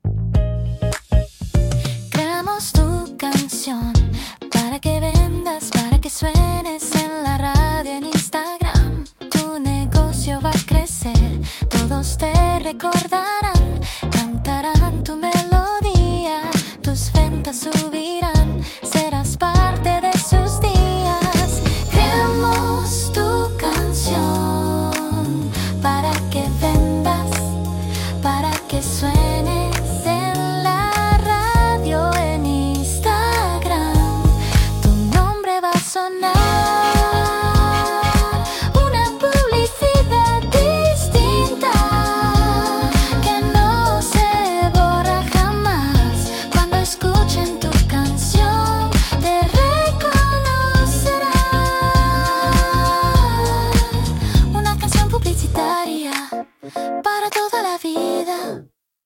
Pop moderno
Pop moderno – Voz femenina